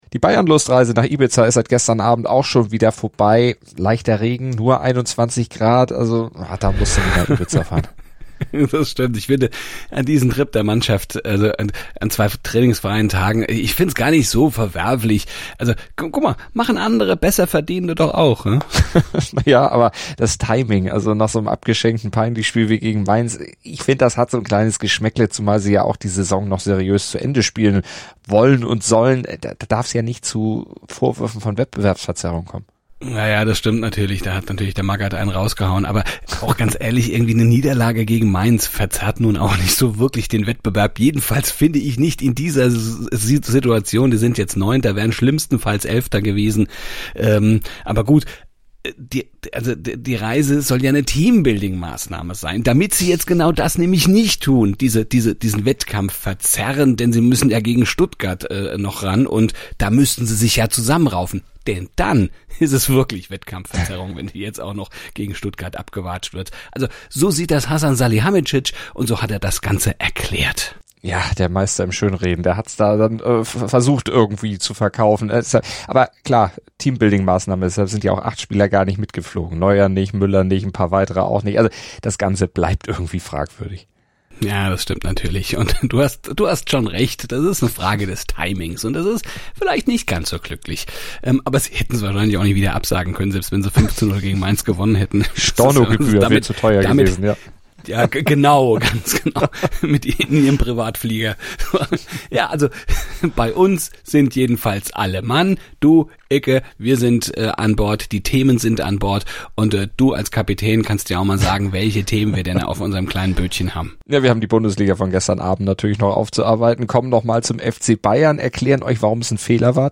Und sie hören Leon Draisaitl zu, wenn er über die Stanley Cup-Chancen seiner Edmonton Oilers spricht.